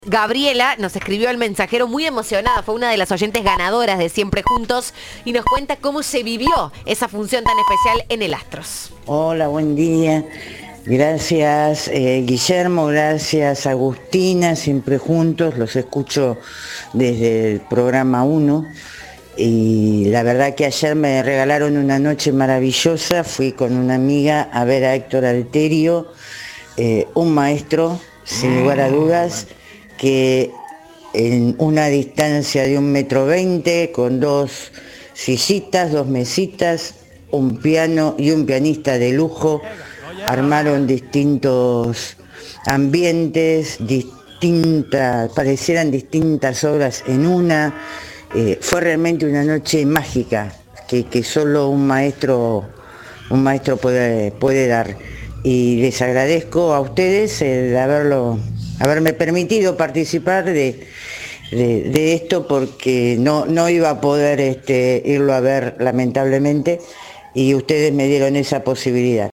"Fue una noche mágica que sólo un maestro puede ofrecer", dijo una oyente invitada por Cadena 3.